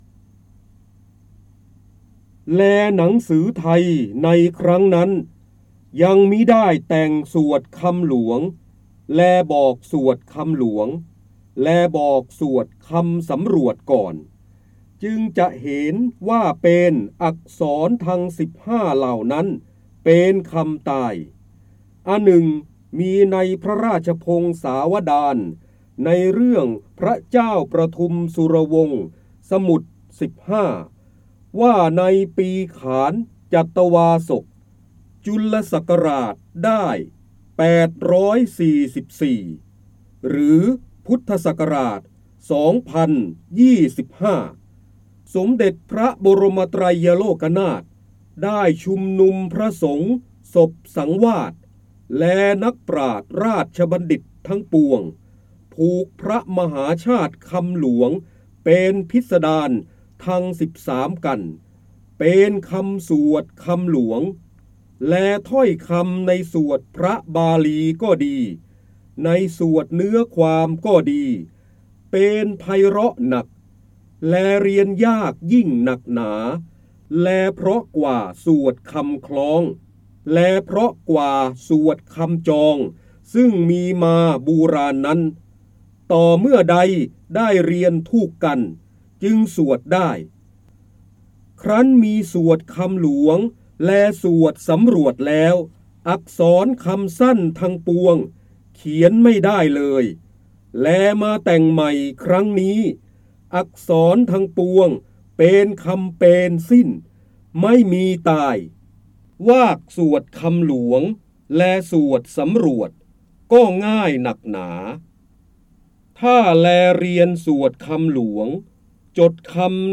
196 137 ตัวอย่าง ดาวน์โหลด ส่ง eCard เสียงบรรยายจากหนังสือ จินดามณี (พระเจ้าบรมโกศ) แลหนังสือไท ในครั้งนั้น ยังมิได้แตง สวดคำหลวง ได้รับใบอนุญาตภายใต้ ให้เผยแพร่-โดยต้องระบุที่มาแต่ห้ามดัดแปลงและห้ามใช้เพื่อการค้า 3.0 Thailand .